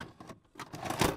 vcr.mp3